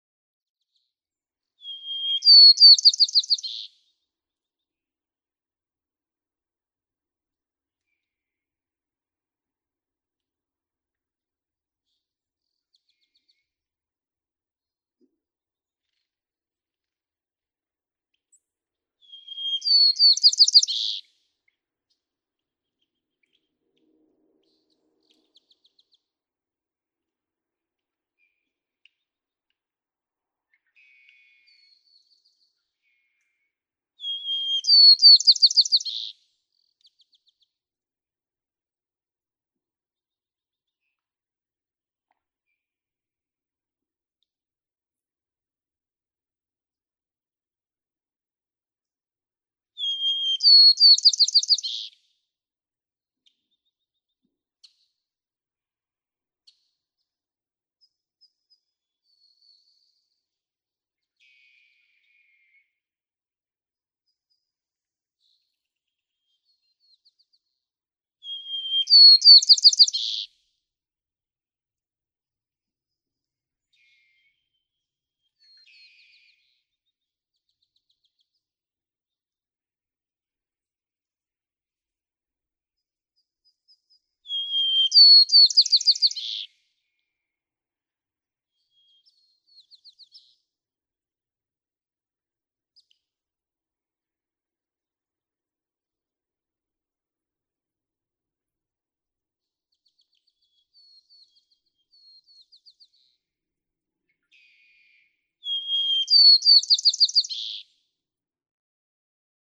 White-crowned sparrow
♫241, ♫242, ♫243—longer recordings from those three individuals
241_White-crowned_Sparrow.mp3